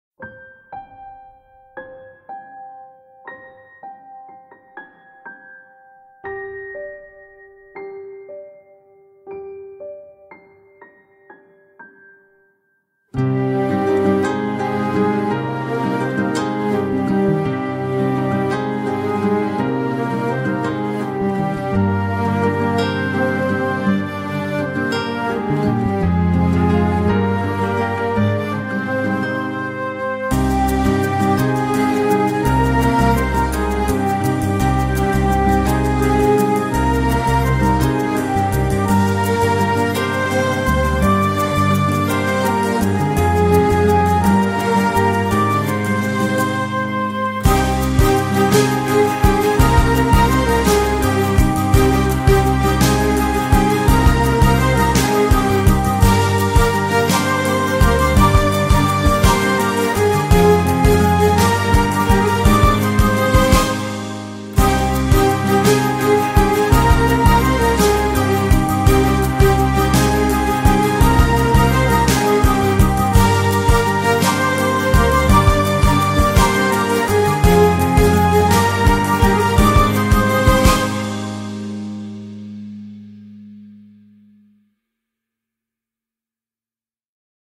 ژانر: لایت بی کلام